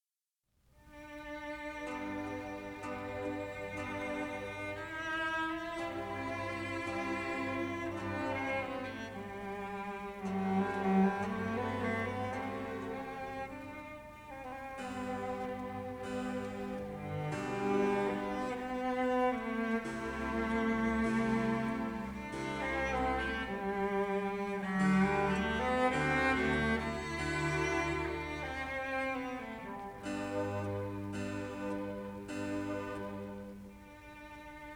Adagio cantabile